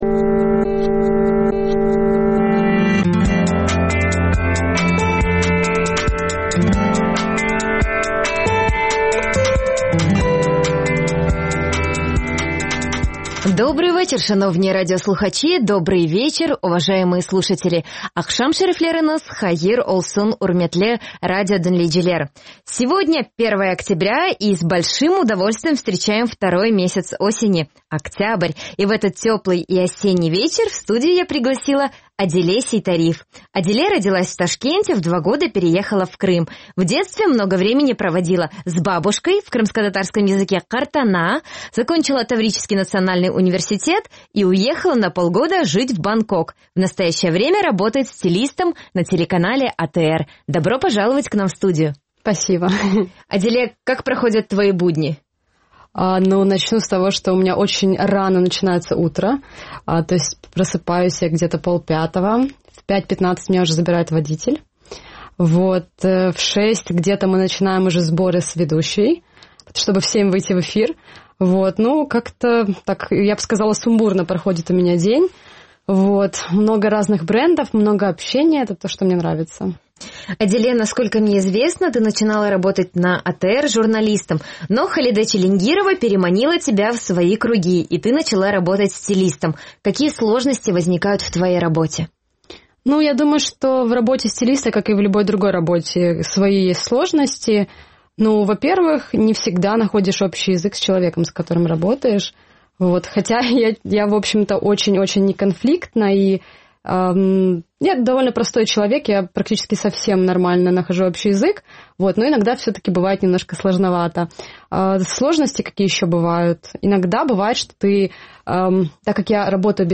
Ефір можна слухати на сайті Крим.Реалії, а також на середніх хвилях на частоті 549 кілогерц.